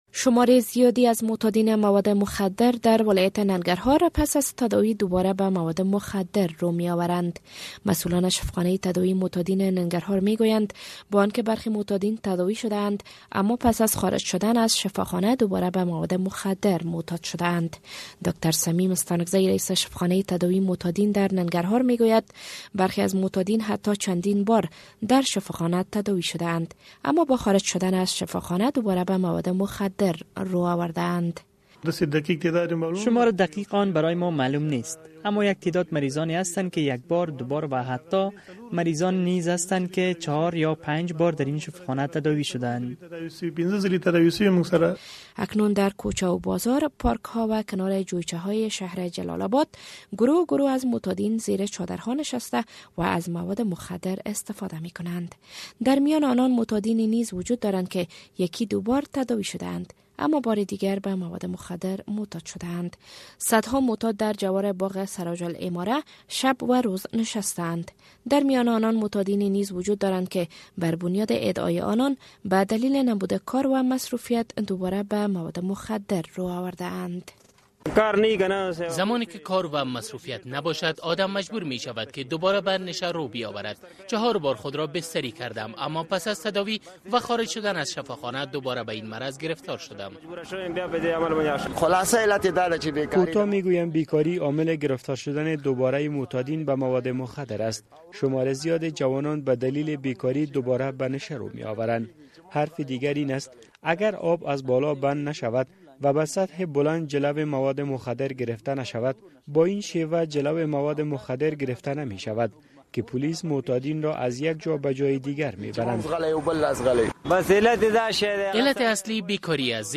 سه تن از معتادین در مورد چنین می‌گویند: